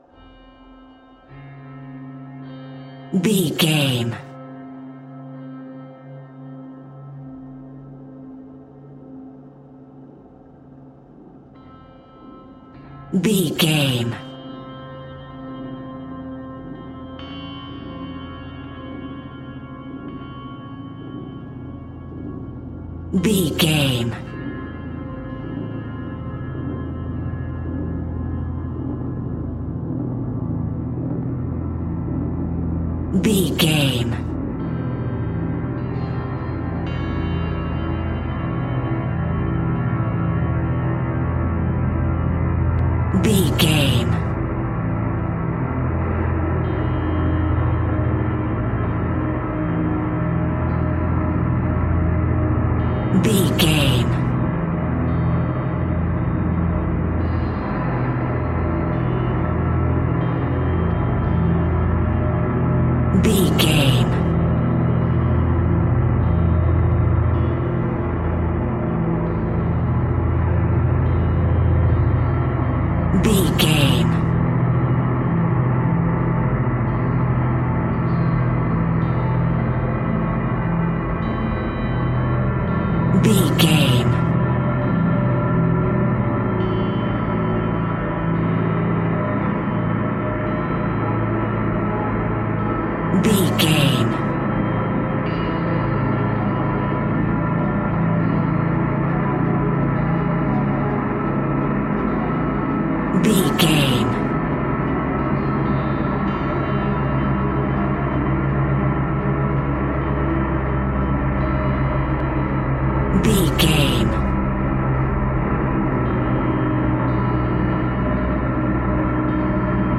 Fast paced
In-crescendo
Ionian/Major
C♯
dark ambient
EBM
drone
synths
Krautrock